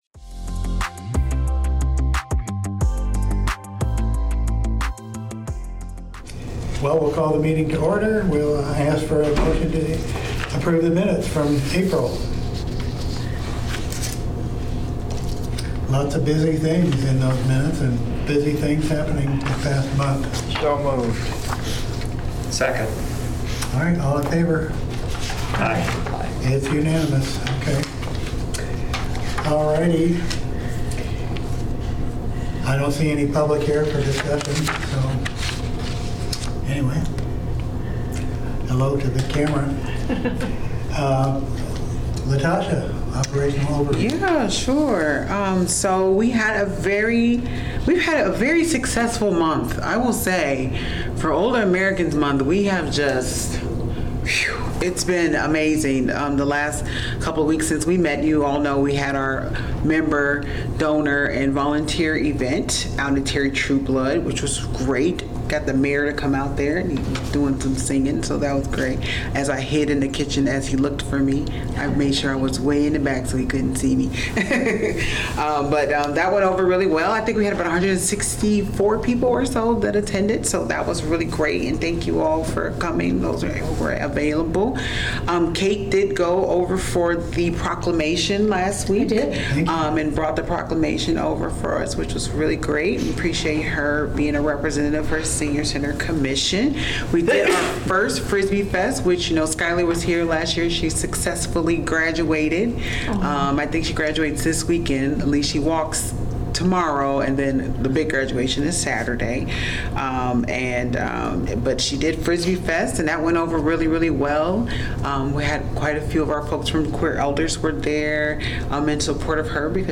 Regular monthly meeting of the Senior Center Commission.